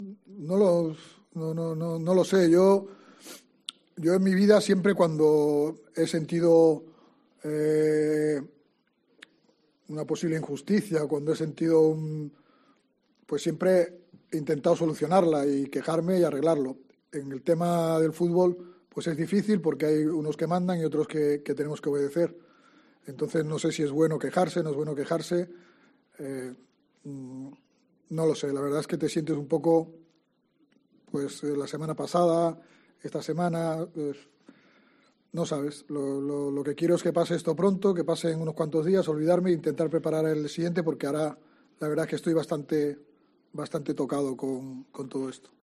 Álvaro Cervera tras el Real Sociedad - Cádiz